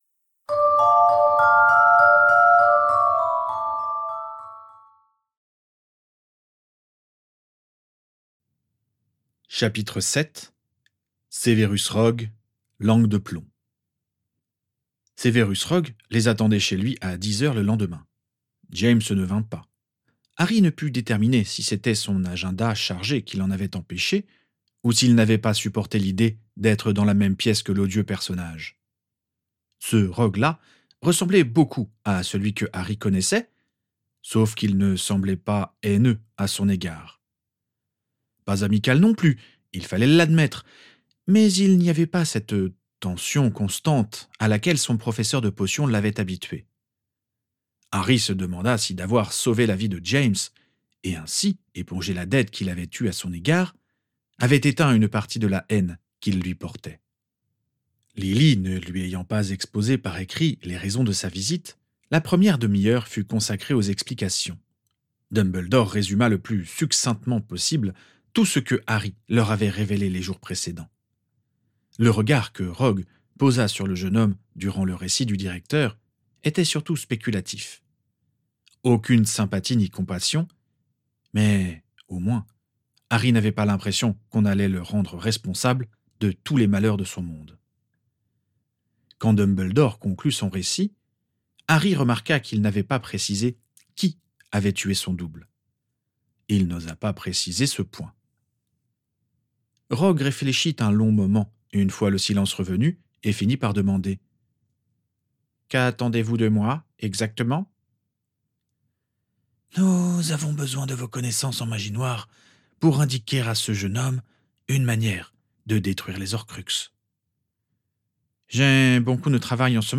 1 Livre audio gratuit : Les Caractères-14- DE LA CHAIRE